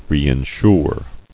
[re·in·sure]